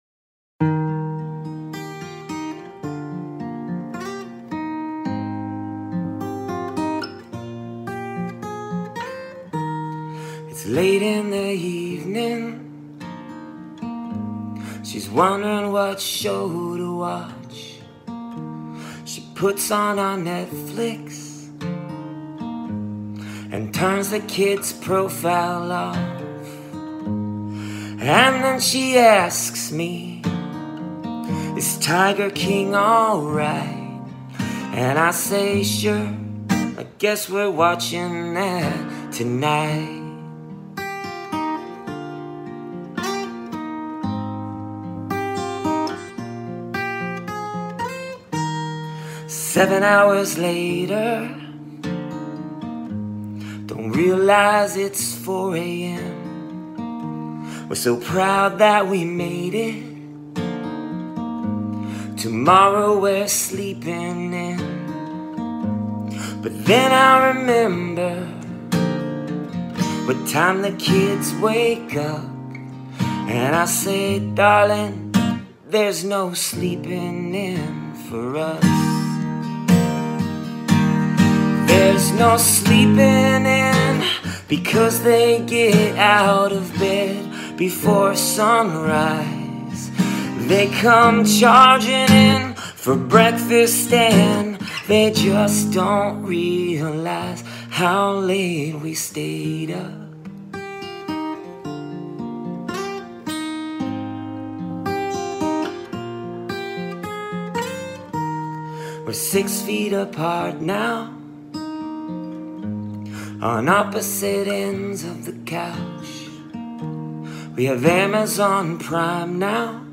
15) A PAIR OF BONUS PARODY SONGS